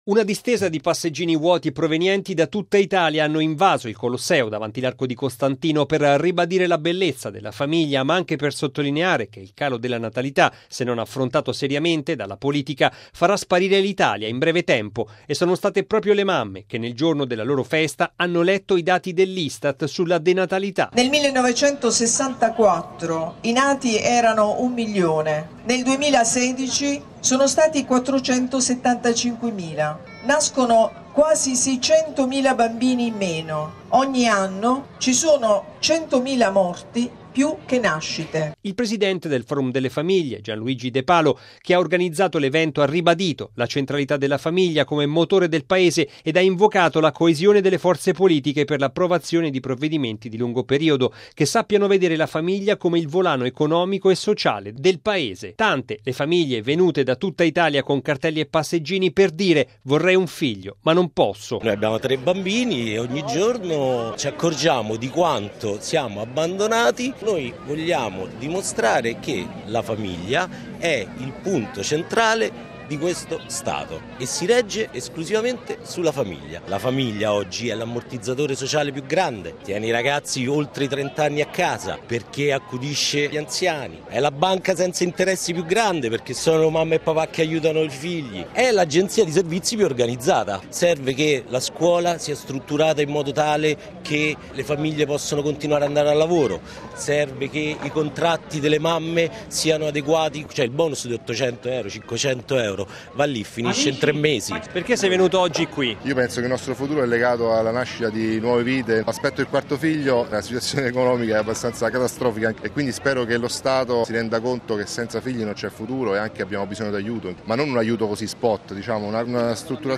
Una distesa di passeggini vuoti, provenienti da tutta Italia, hanno invaso il Colosseo, davanti l’Arco di Costantino, per ribadire la bellezza della famiglia, ma anche per sottolineare che il calo della natalità se non affrontato seriamente dalla politica farà sparire l’Italia in breve tempo, e sono state proprio le mamme, che nel giorno della loro festa, hanno letto i dati dell’Istat sulla denatalità:
Tante le famiglie venute da tutta Italia con passeggini e cartelli con scritto: “Vorrei un figlio ma non posso”: